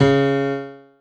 Nota Dó Sustenido identificada através do Método do Produto Interno
Estes sons fazem parte de um banco de notas sintetizadas artificialmente com o timbre de um piano e foram disponibilizadas na internet.